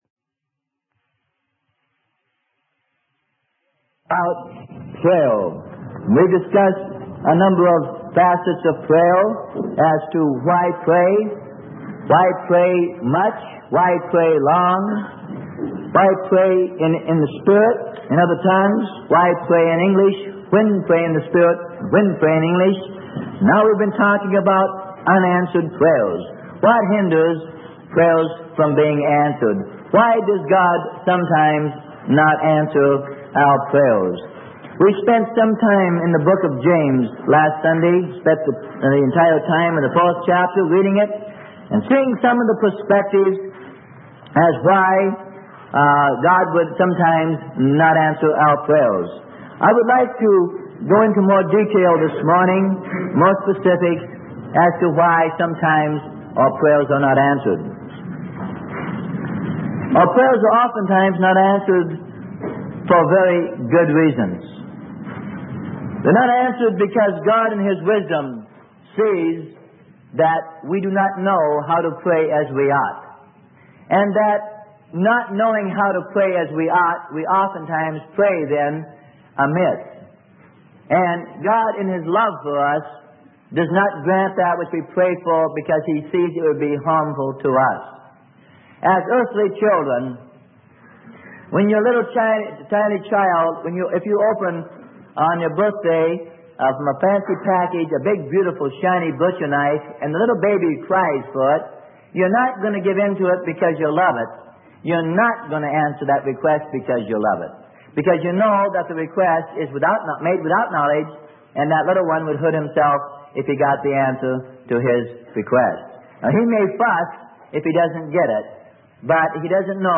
Sermon: How to be Led of God - Part 23 - Unanswered Prayer - Freely Given Online Library